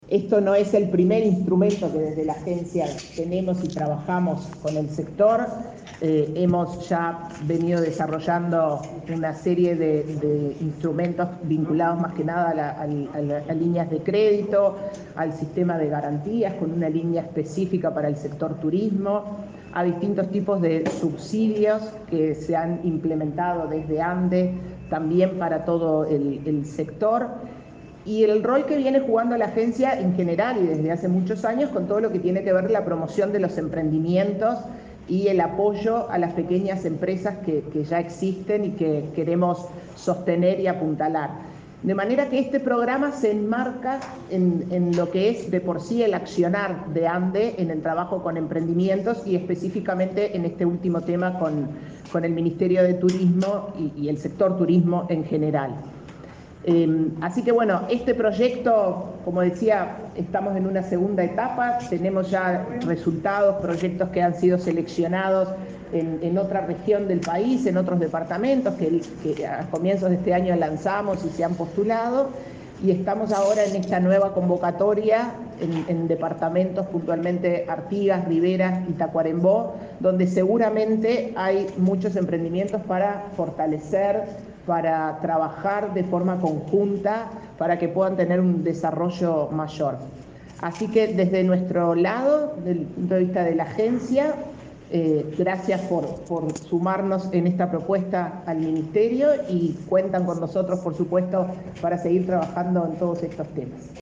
Palabras de la presidenta de ANDE, Carmen Sánchez
La presidenta de la Agencia Nacional de Desarrollo (ANDE), Carmen Sánchez, participó en la presentación del segundo llamado del fondo concursable para